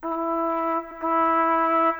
Cinematic 27 Horns 01.wav